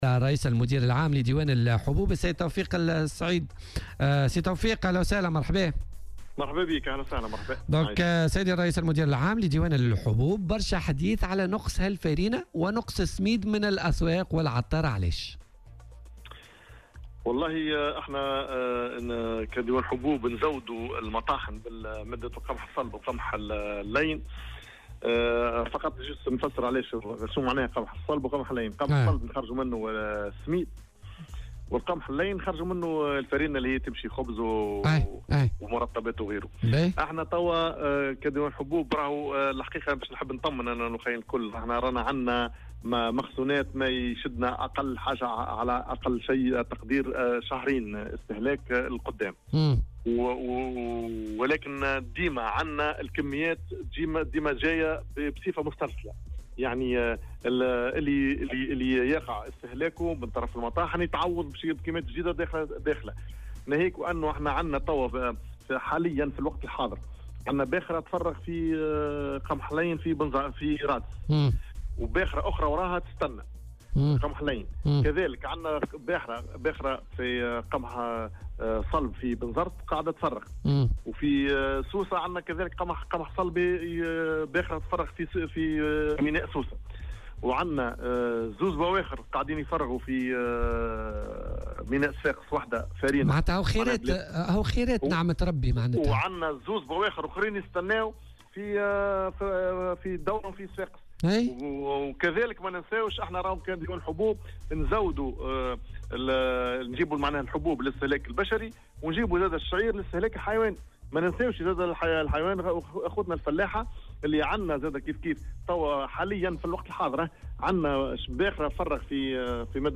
وأضاف في مداخلة له اليوم في برنامج "بوليتيكا" أن عمليات تزويد المطاحن بالقمح اللين ( الفارينة) والقمح الصلب (السميد) يتم بصفة مستمرة، مشيرا إلى أن ما يتم استهلاكه يقع تعويضه آليا (إفراغ شحنة باخرة حاليا في ميناء رادس محمّلة بالقمح اللين تليها باخرة ثانية وباخرة أخرى محملة بالقمح الصلب في بنزرت وباخرة قمح صلب في ميناء سوسة وباخرتين في صفاقس).ودعا السعيدي المواطنين إلى الابتعاد عن اللهفة، موضحا أن الكميات المتوفرة في الأسواق كافية.